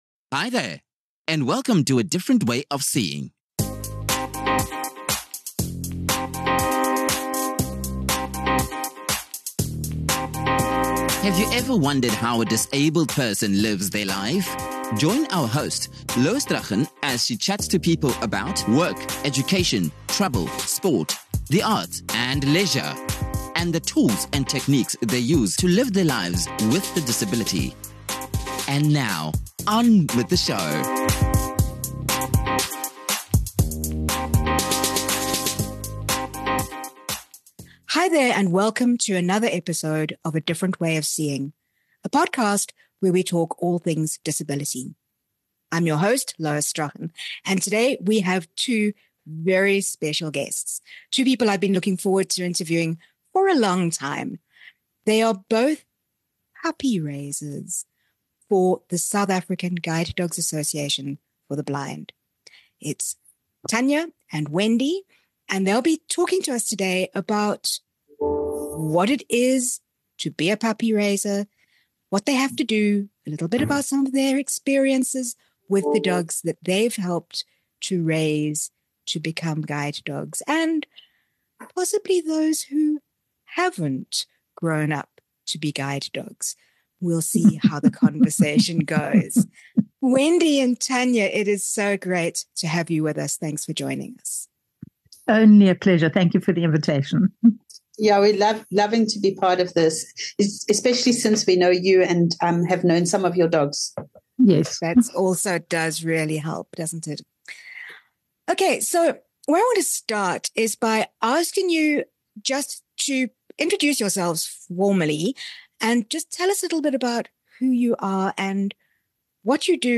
On January 12, 2015, both shows launched as a part of WABE’s format shift to news and talk radio.